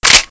assets/psp/nzportable/nzp/sounds/weapons/mg42/magout.wav at af6a1cec16f054ad217f880900abdacf93c7e011